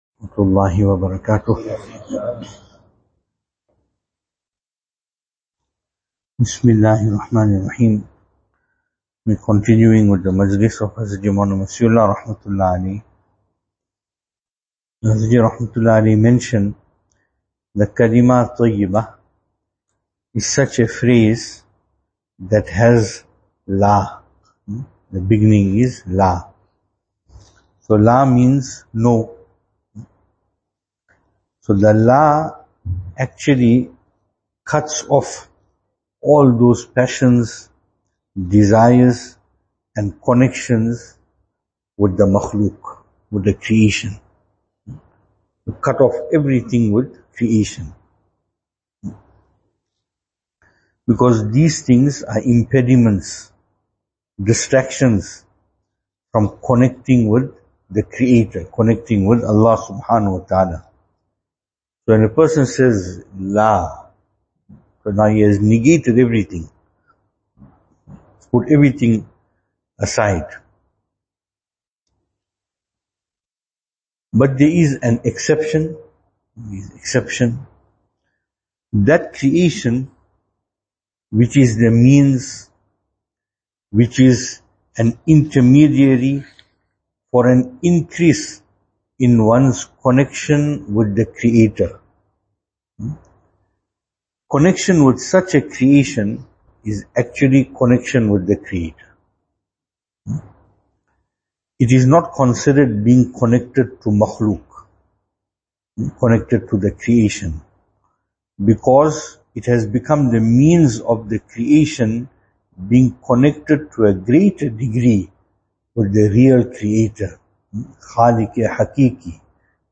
Venue: Albert Falls , Madressa Isha'atul Haq Service Type: Zikr